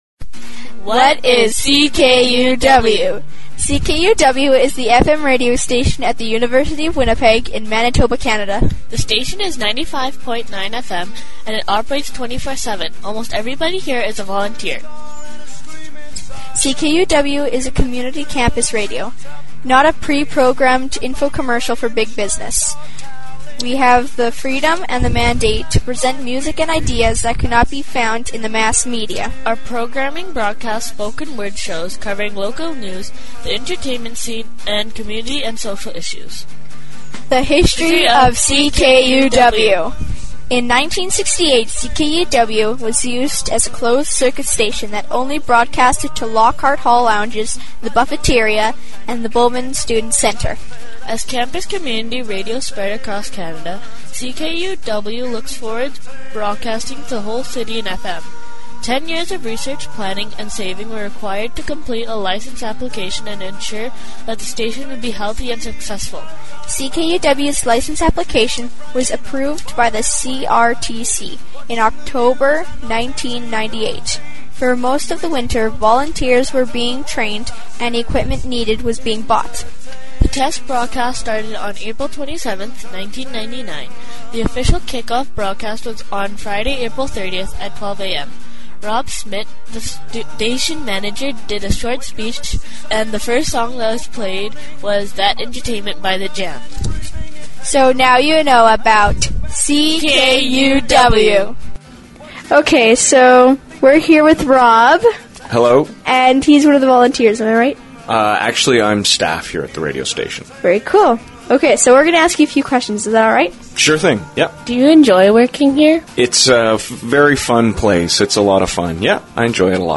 Last weeks campers put together this really cool documentary about CKUW. They interview a few of our staff and some volunteers.